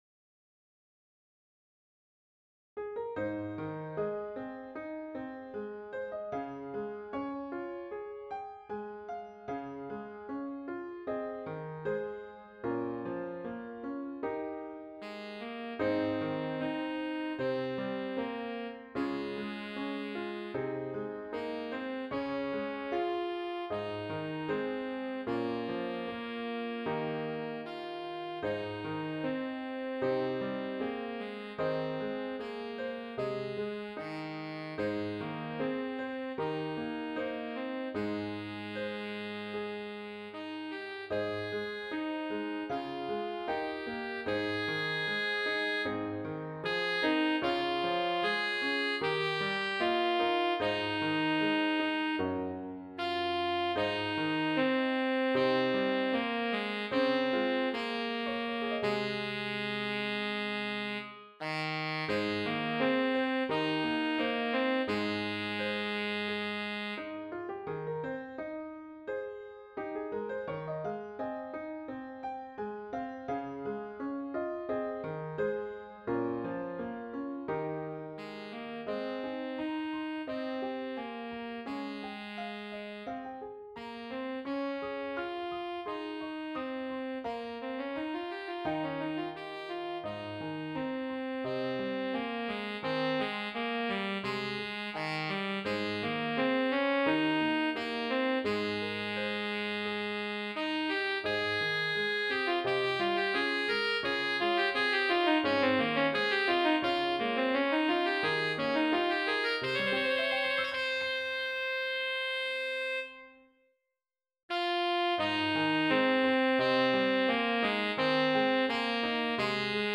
Intermediate Instrumental Solo with Piano Accompaniment.
Christian, Gospel, Sacred, Folk.
put to a flowing folk setting.